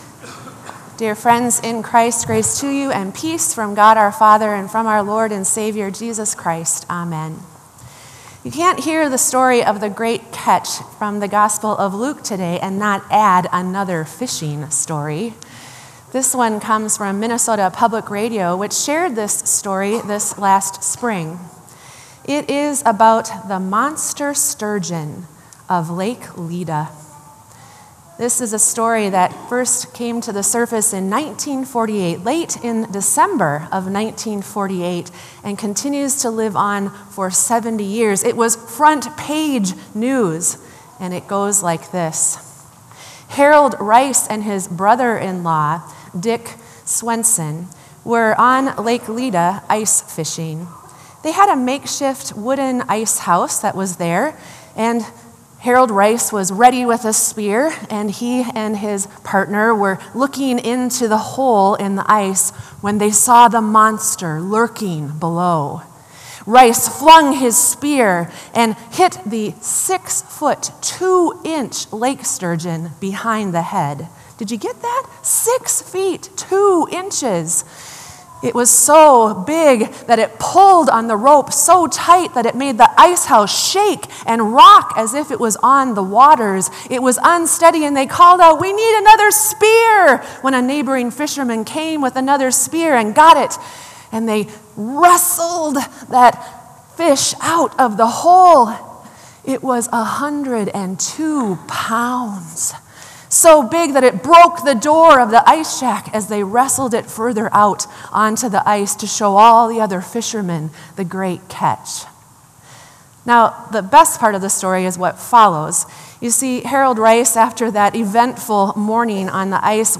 Sermon “The Great Catch”